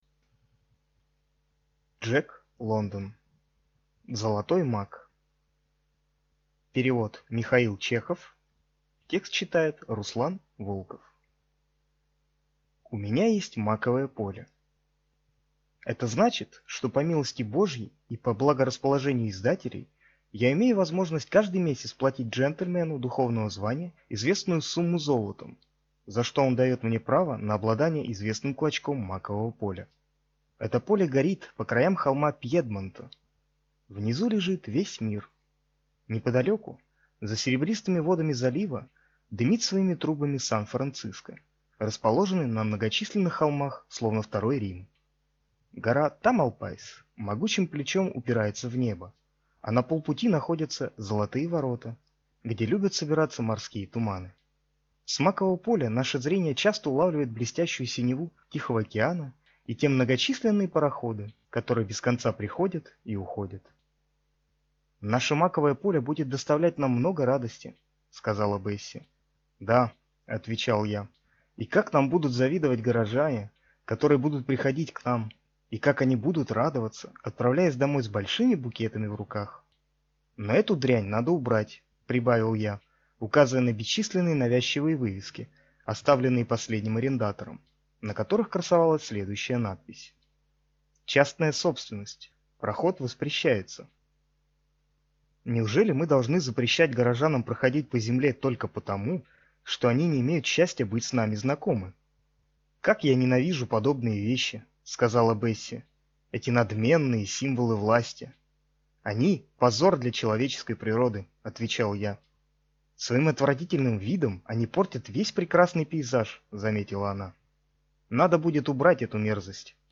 Аудиокнига Золотой мак | Библиотека аудиокниг